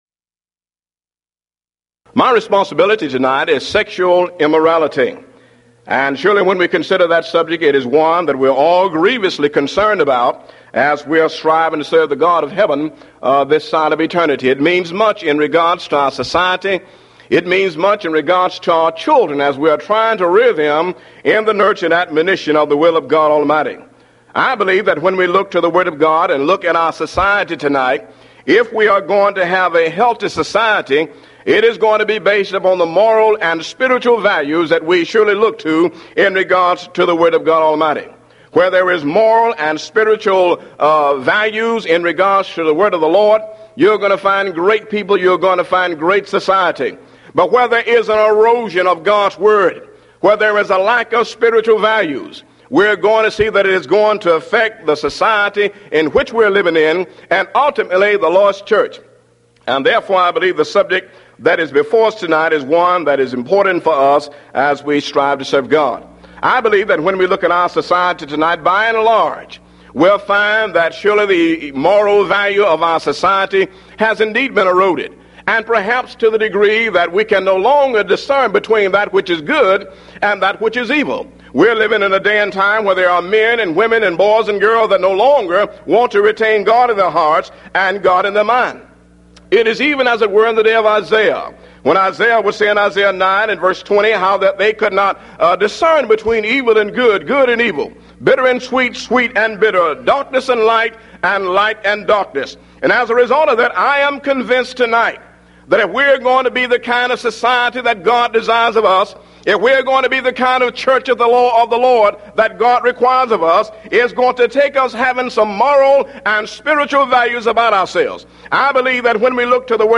Event: 1993 Mid-West Lectures Theme/Title: The Christian Family
lecture